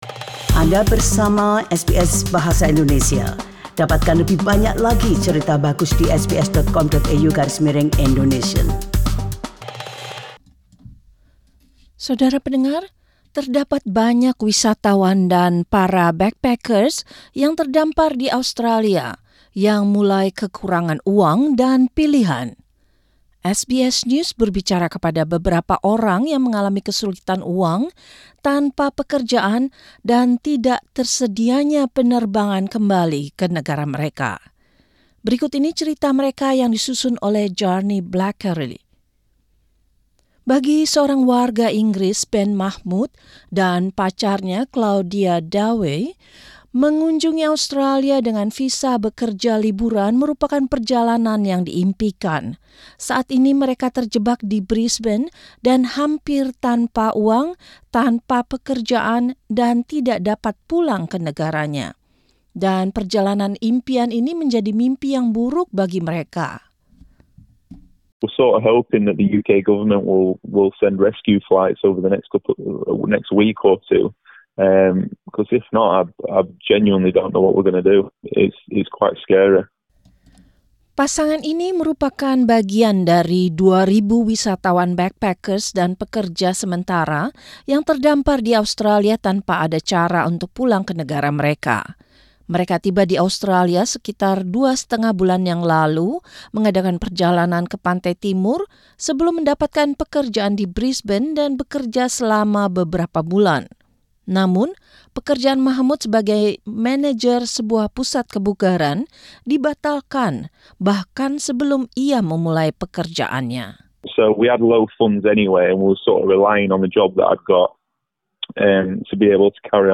How can they survive, while almost all flights are cancelled? You can listen in this report.